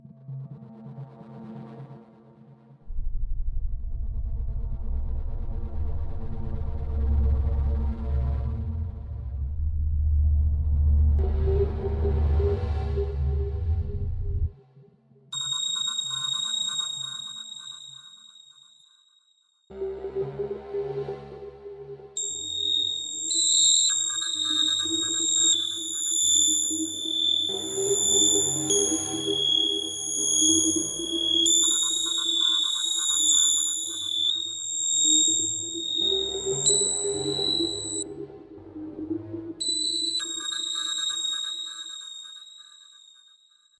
Tag: 通过 FL 合成器 工作室 创建 低音 声音